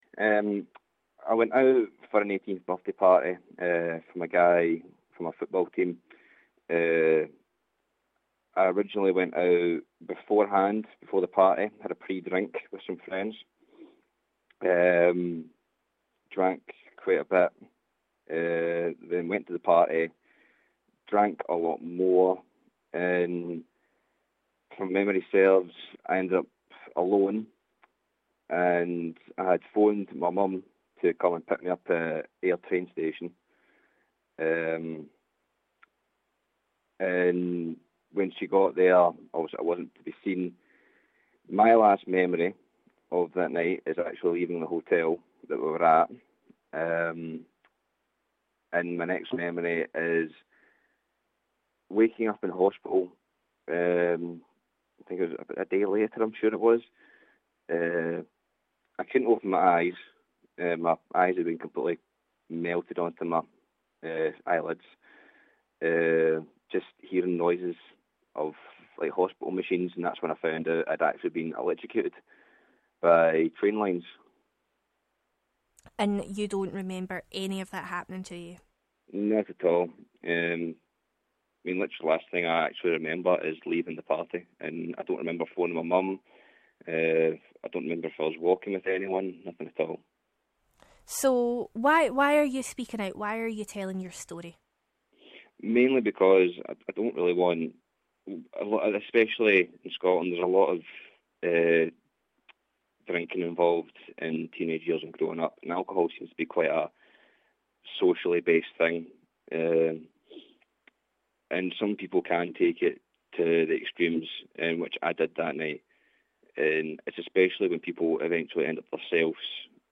Ayrshire man speaks about binge drinking accident to stop youngester making same mistake...